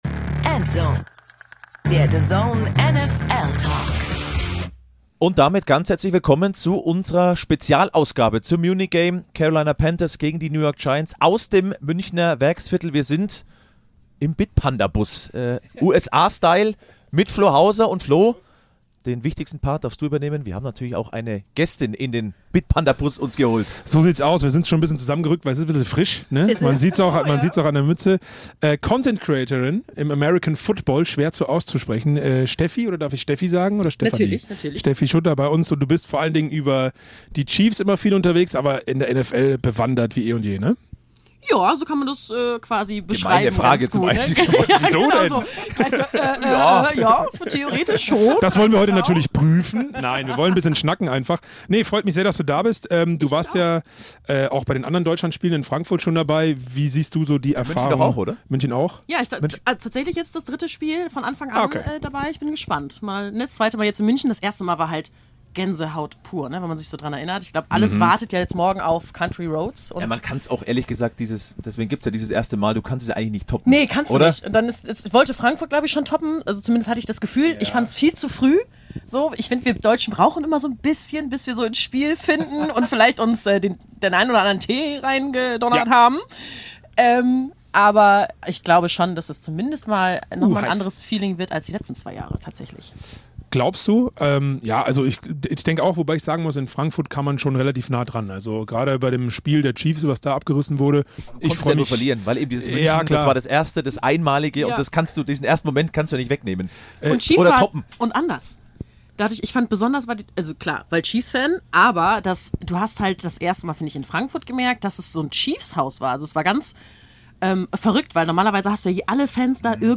Die Carolina Panthers treffen auf die New York Giants und wir sitzen im Bitpanda-Bus im Münchner Werksviertel und reden über das Spiel.
Zum Schluss bekommt ihr noch die frischen DAZN-Interviews mit den Giants und den Panthers auf die Ohren und zwar in folgender Reihenfolge: Malik Nabers, Daniel Jones, Dexter Lawrence, Jakob Johnson und dann natürlich noch Bryce Young.